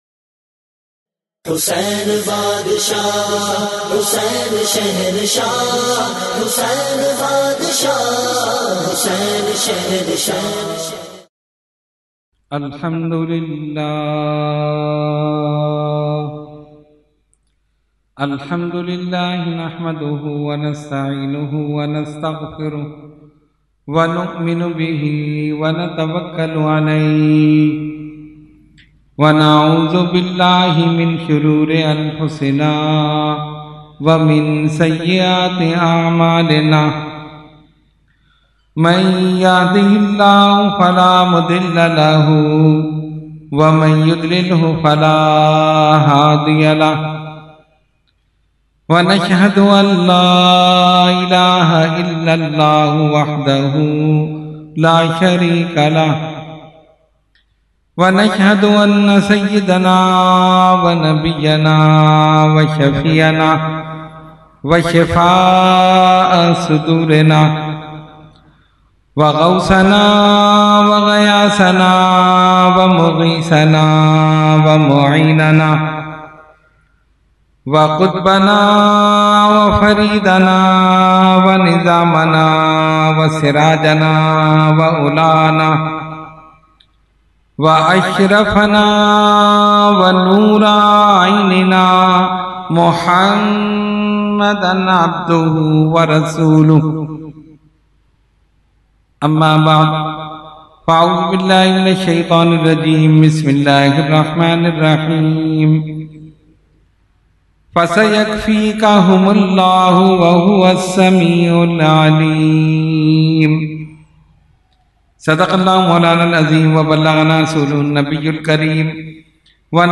9 Roza Mehfil e Muharram ul Haram held on 1st Muharram ul Haram to 9th Muharram ul Haram at Jamia Masjid Ameer Hamza Nazimabad Karachi.
Category : Speech | Language : UrduEvent : Muharram 2021